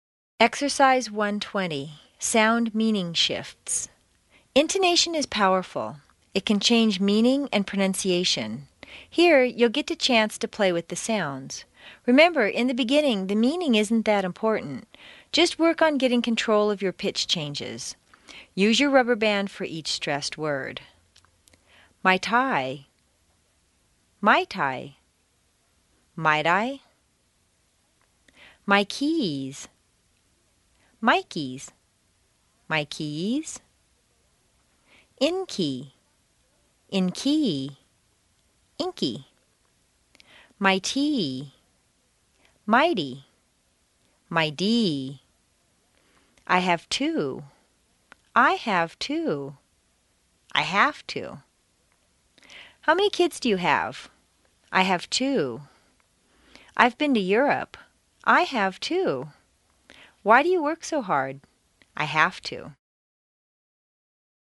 美语口语发音训练 第一册29
Exercise 1-20; Sound/Meaning Shifts CD 1 Track 29
Intonation is powerful.